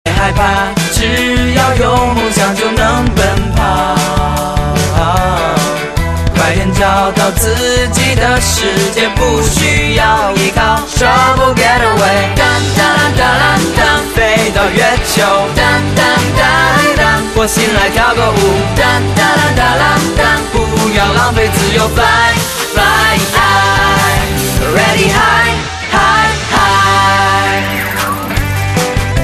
M4R铃声, MP3铃声, 华语歌曲 85 首发日期：2018-05-15 01:54 星期二